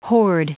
Prononciation du mot hoard en anglais (fichier audio)
Prononciation du mot : hoard